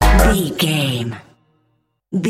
Aeolian/Minor
G#
drum machine
synthesiser
hip hop
Funk
acid jazz
energetic
bouncy
funky
hard hitting